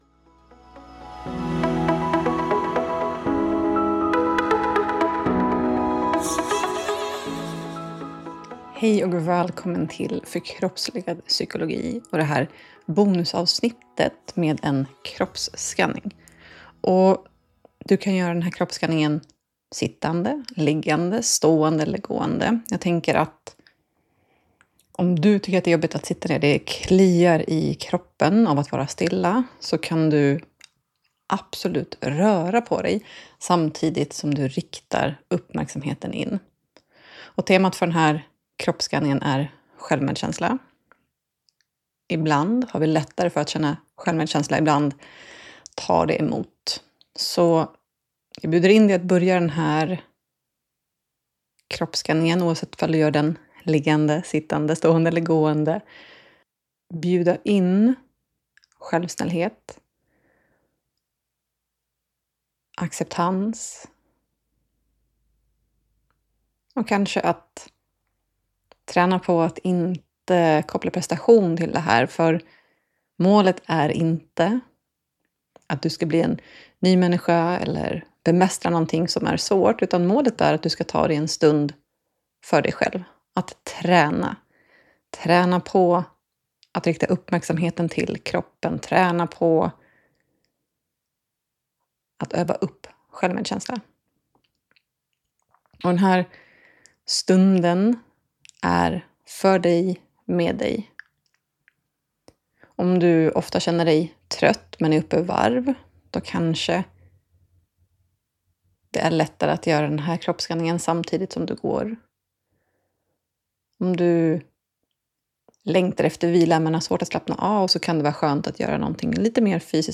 I det här bonusavsnittet bjuder jag på en kroppsscanning för självmedkänsla.